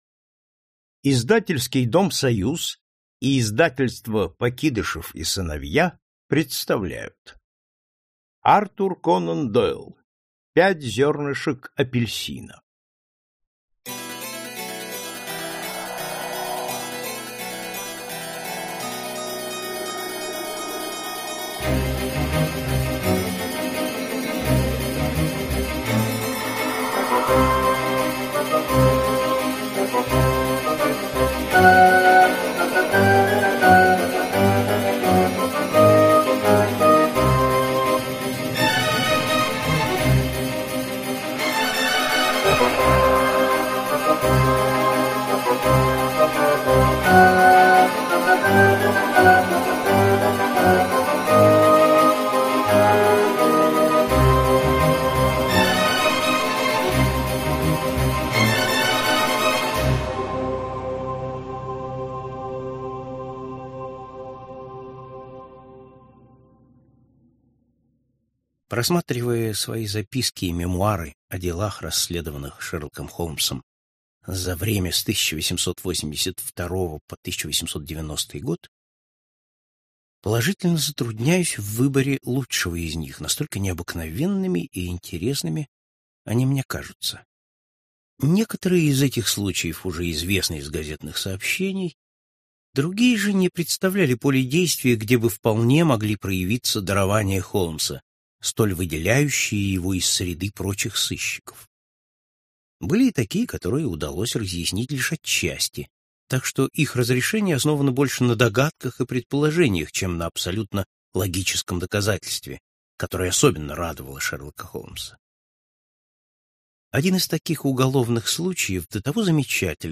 Аудиокнига Пять зернышек апельсина | Библиотека аудиокниг
Прослушать и бесплатно скачать фрагмент аудиокниги